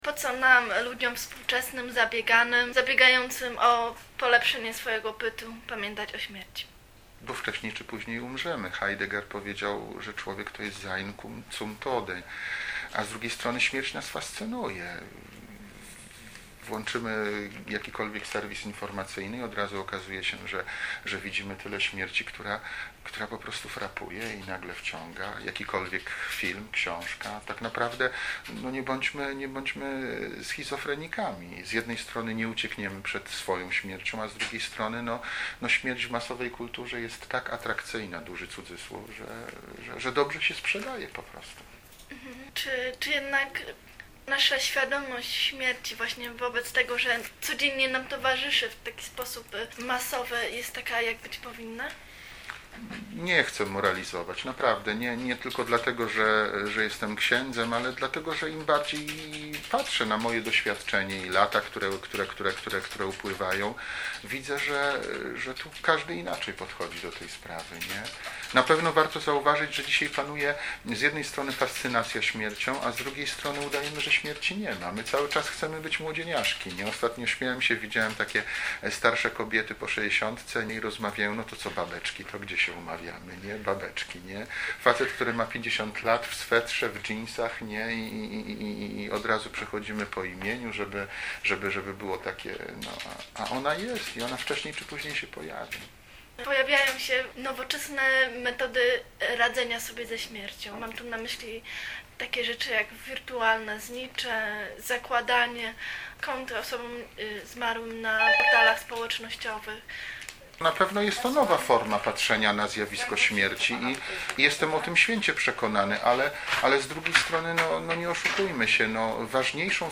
rozmowa
Z ksi�dzem, który nie moralizuje, nie poucza, a rozmawia.